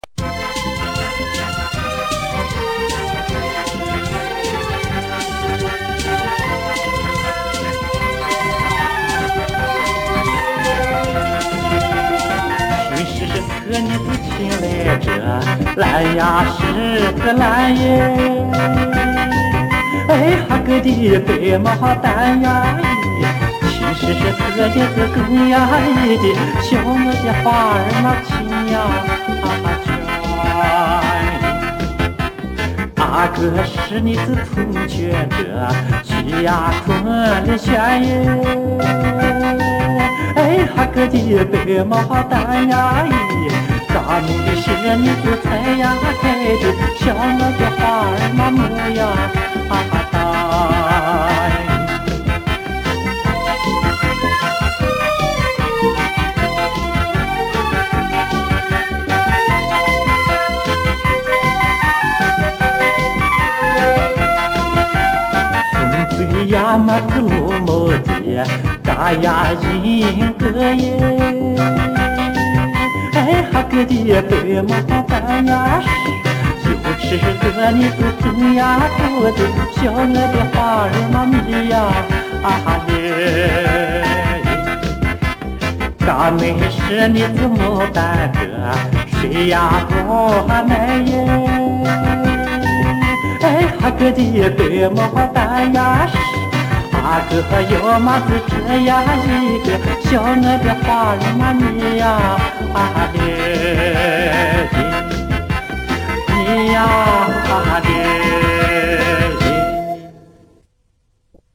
首页 > 图文板块 > 临夏花儿
青海花儿 - 尕妹是才开的牡丹.mp3